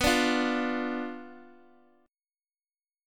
Bdim chord